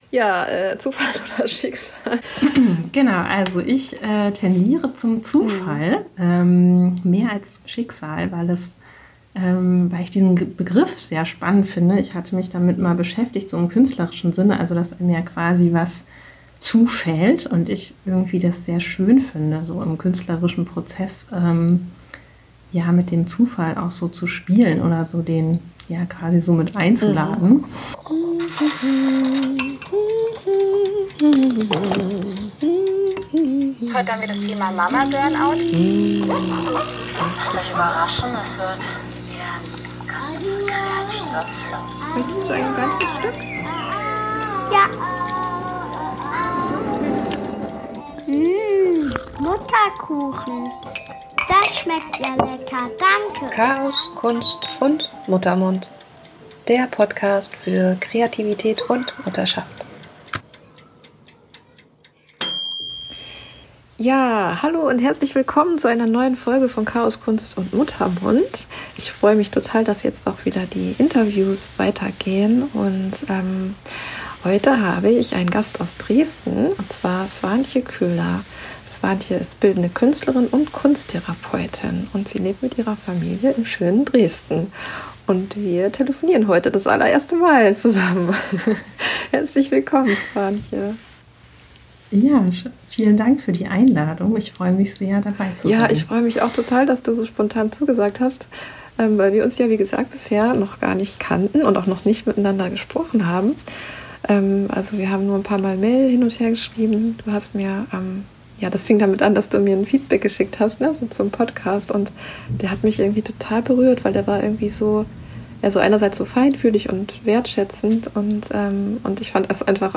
Die Parallelen zwischen der künstlerischen Arbeit und der Therapie. Über Kunst und Gefühle und wie alles zu seiner Zeit kommen bzw gehen darf. Freut euch auf ein feinfühliges, gemütliches Gespräch mit Tiefgang!